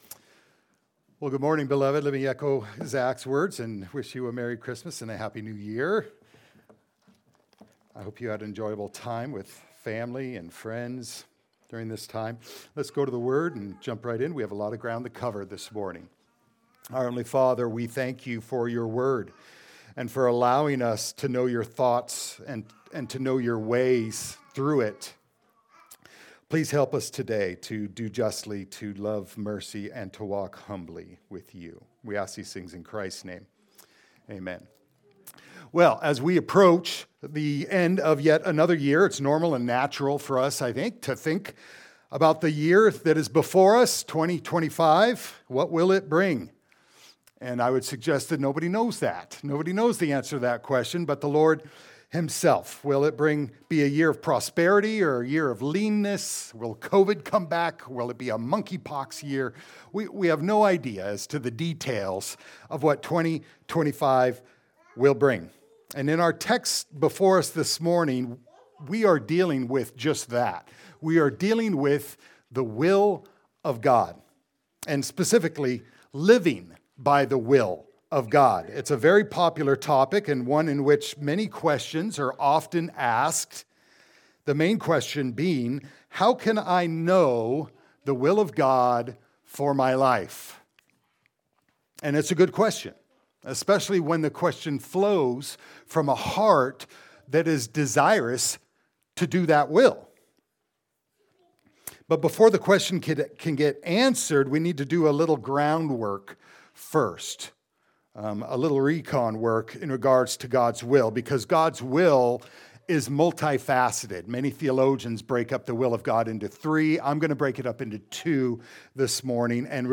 James 4:13-17 Service Type: Sunday Service « “Jonah…